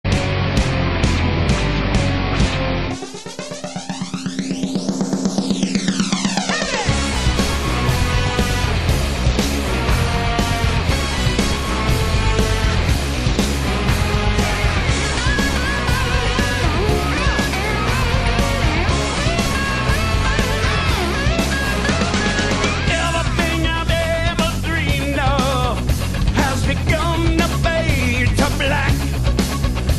opera-like voice and sound